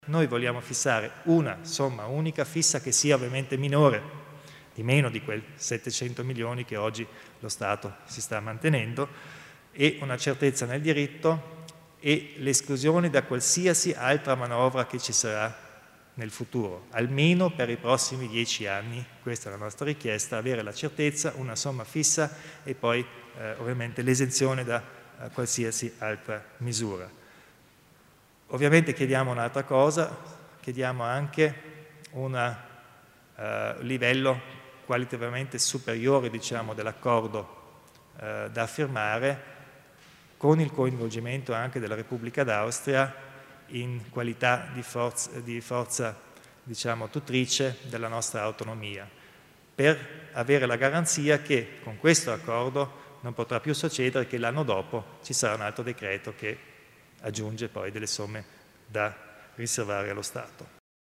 Il Presidente Kompatscher illustra le trattative tra Stato e Provincia in tema di finanza